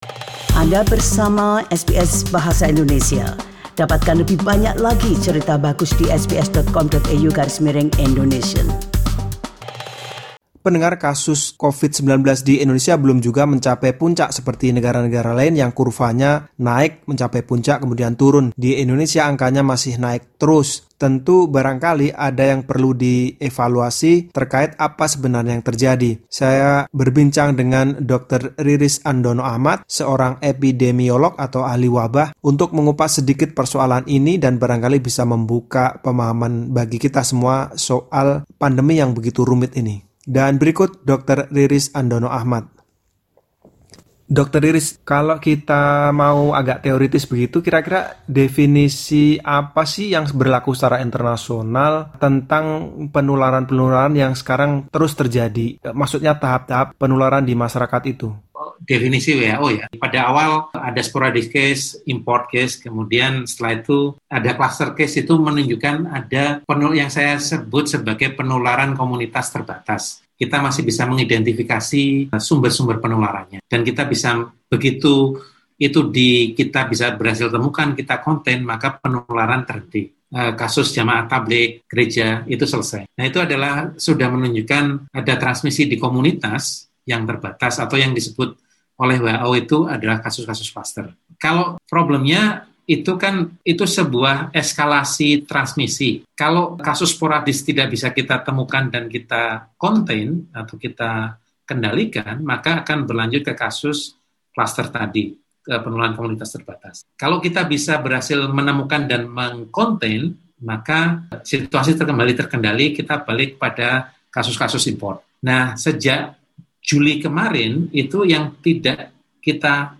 Perbincangan dengan epidemiolog atau ahli wabah